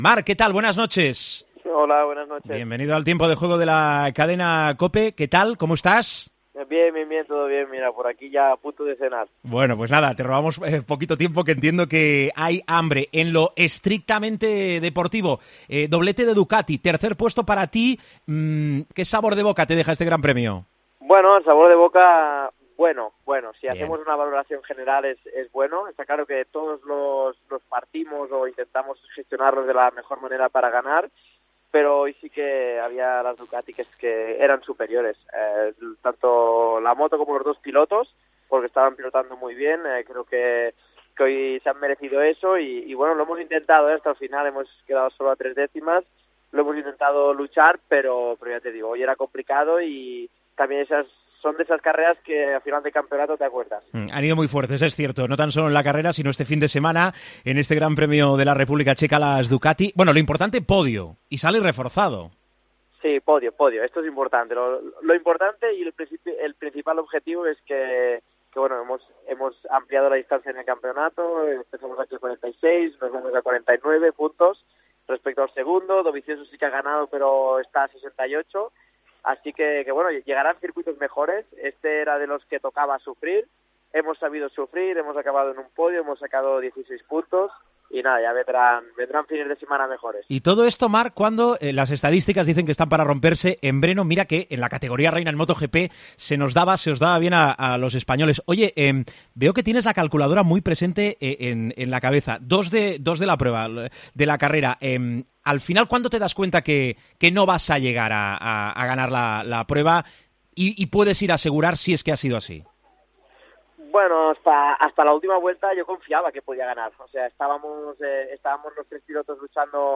Hablamos con Marc Marquez, que ha terminado en tercer puesto el Gran Premio de la República Checa: "Veo a las dos Ducati y a Valentino muy bien"